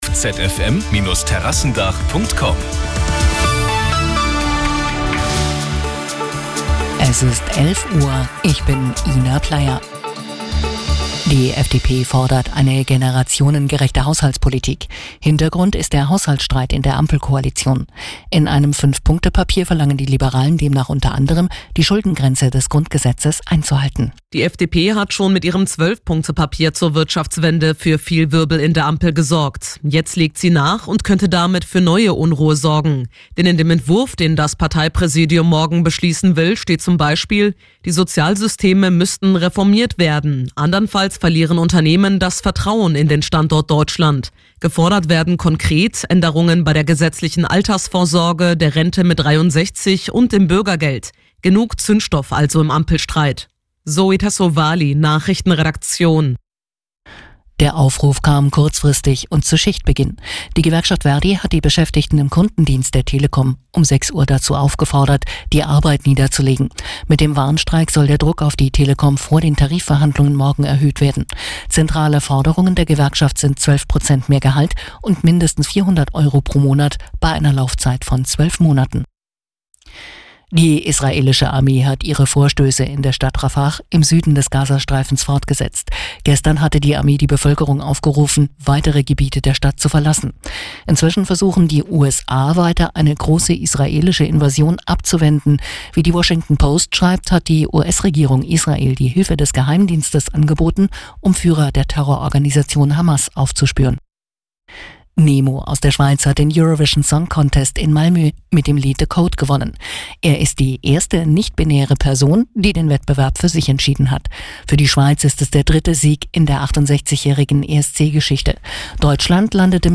Die Arabella Nachrichten vom Sonntag, 12.05.2024 um 11:06 Uhr - 12.05.2024